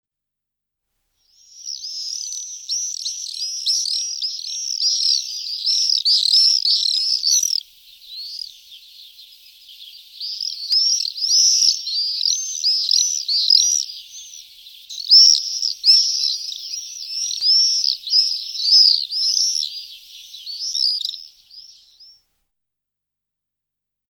Погрузитесь в мир звуков стрижей – их звонкое щебетание напомнит о теплых летних днях.
Звук стаи черных стрижей, чирикающих в небе